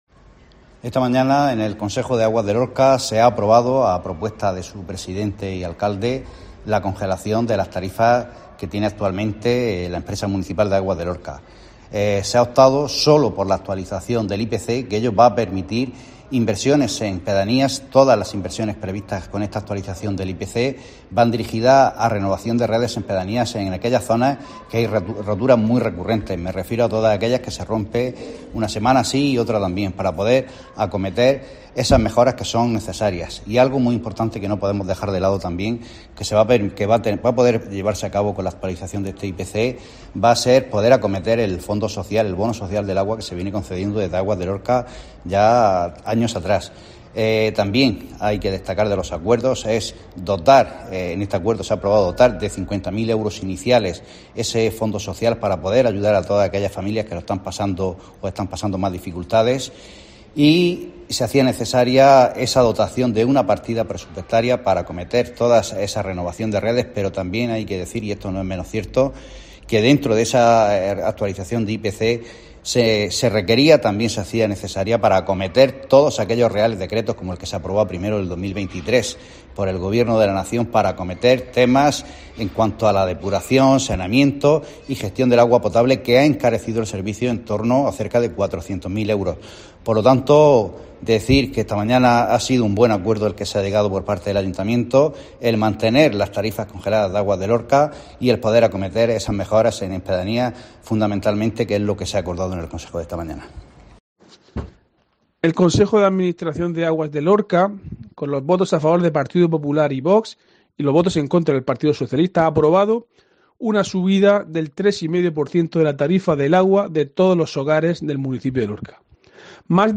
Ángel Meca, concejal del PP y Diego José Mateos, portavoz del PSOE